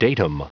Prononciation du mot datum en anglais (fichier audio)
Prononciation du mot : datum